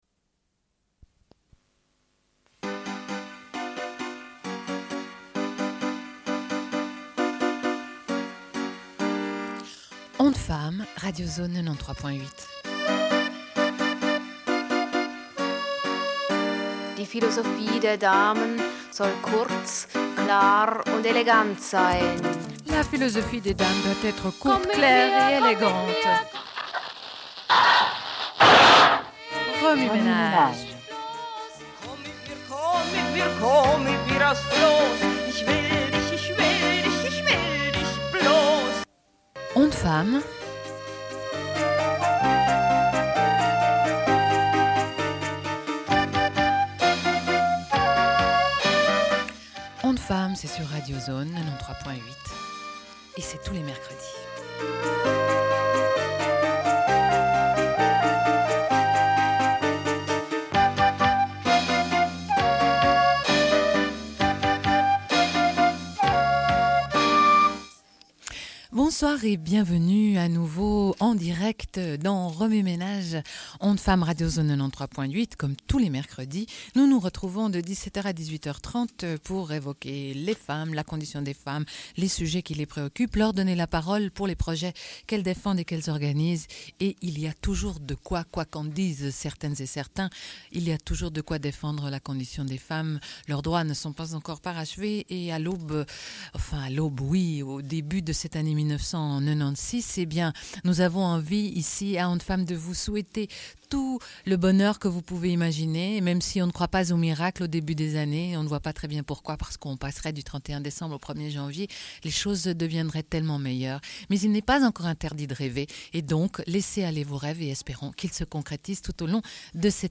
Deuxième partie de l'émission, rediffusion d'un entretien avec les Reines prochaines.